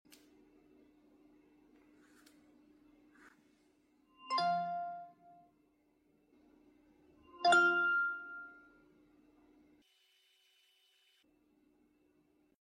iPhone shutdown and startup chime sound effects free download